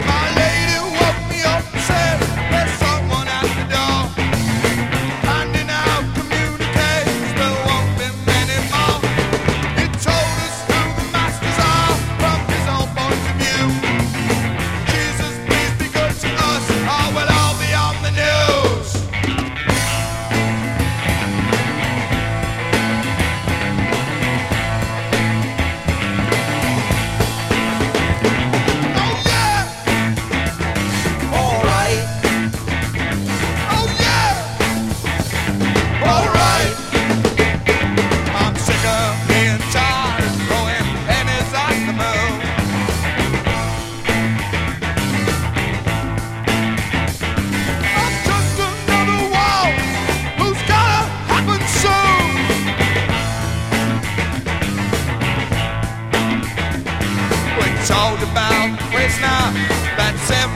英国ハードロック